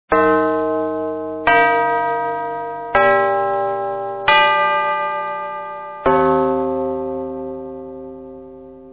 Campane tubolari
Ieratico, solenne.
campane.mp3